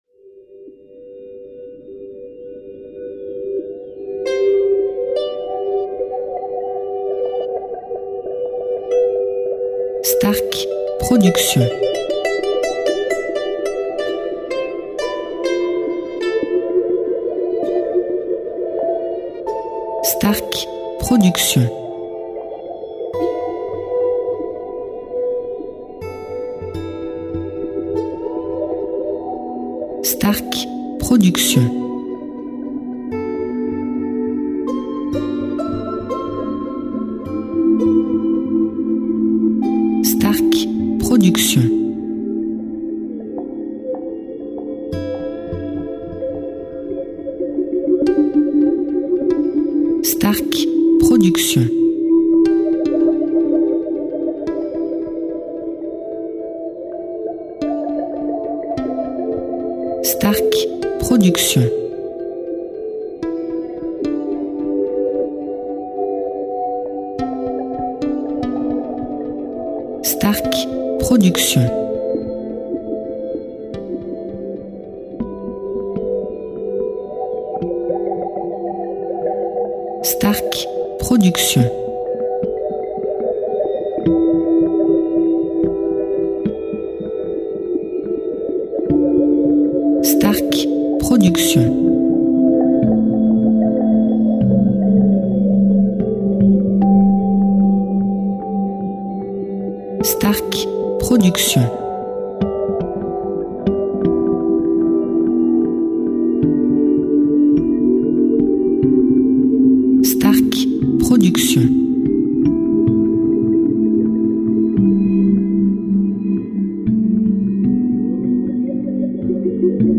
style NewAge Worldmusic durée 1 heure